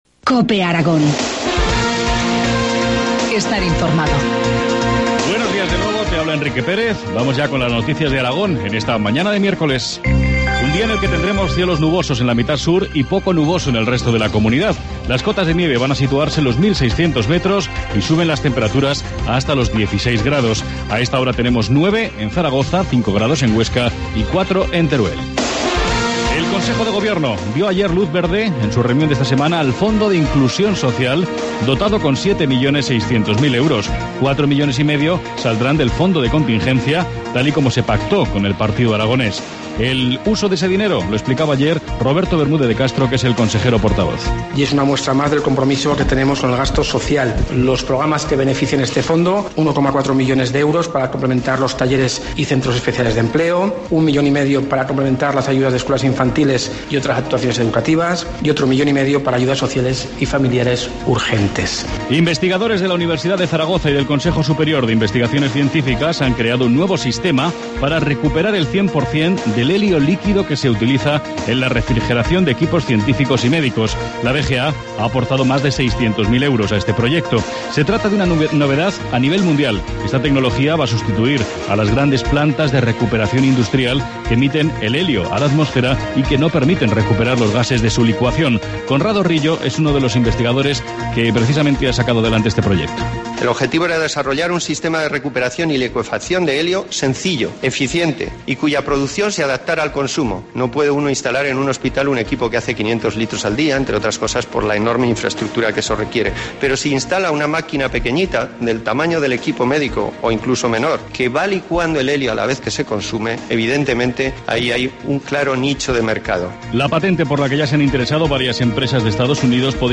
Informativo matinal, miércoles 20 de febrero, 8.25 horas